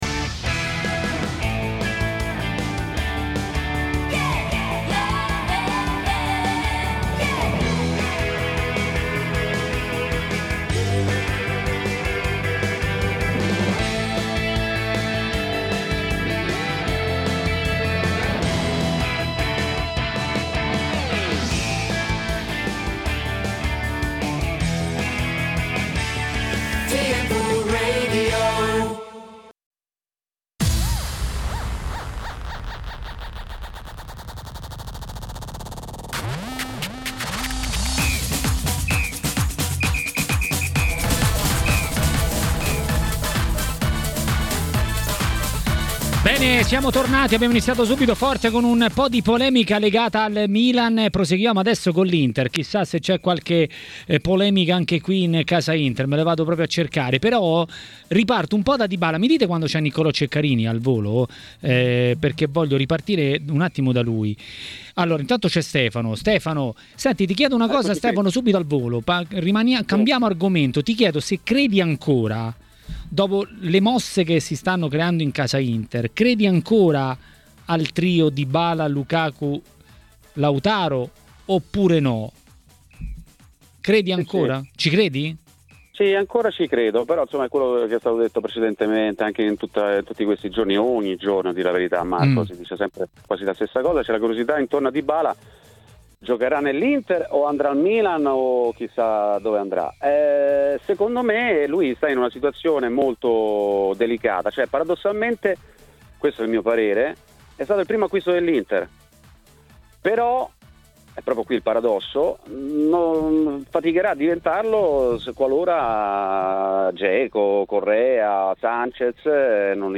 A Maracanà, trasmissione di TMW Radio, è arrivato il momento dell'ex calciatore e giornalista